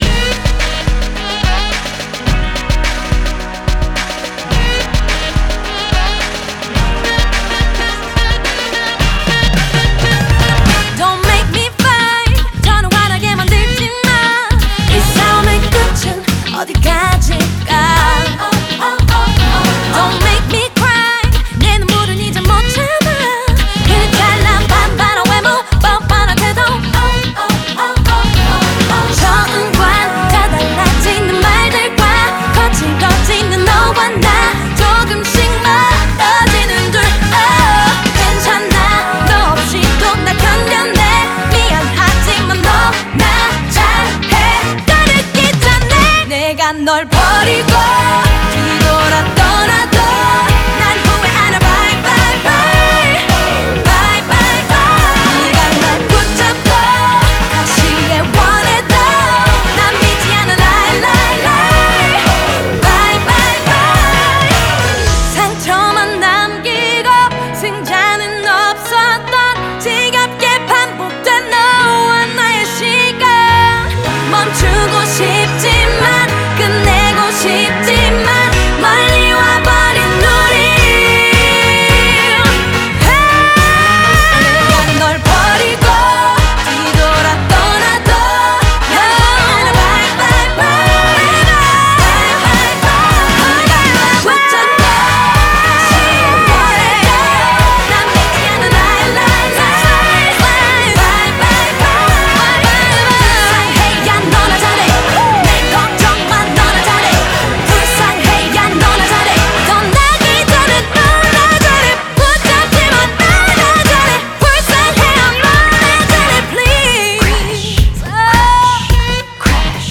BPM107